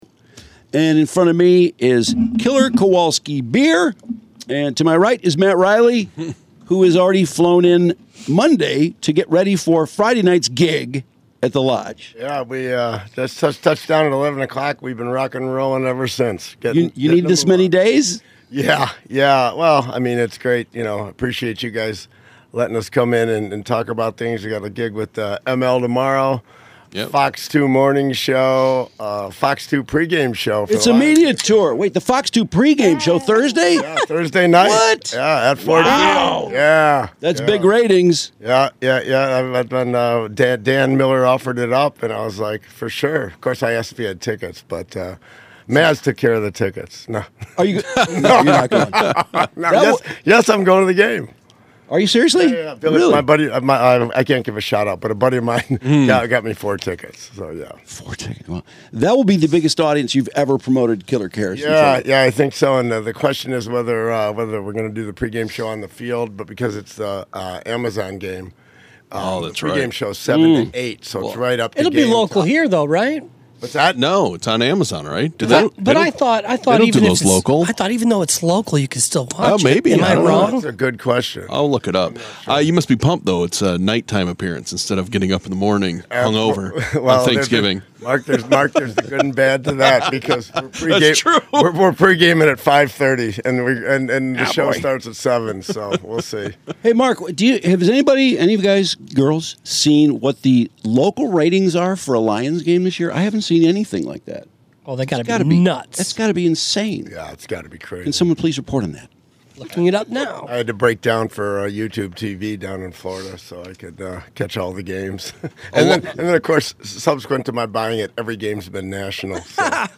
showed up hung over with no voice at all